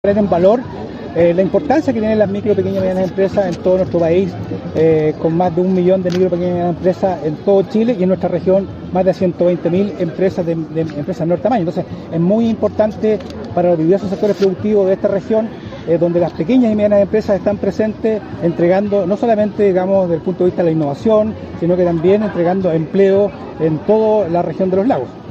Luis Cárdenas, Seremi de Economía, comentó que en la región de Los Lagos las Micro, Pequeñas y Medianas Empresas superan las 120 mil y recalcó su importancia, por ejemplo, en la generación de empleo.